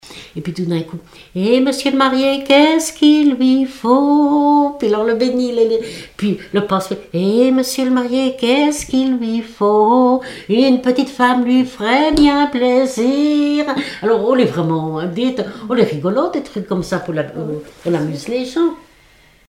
circonstance : fiançaille, noce
Genre énumérative
Catégorie Pièce musicale inédite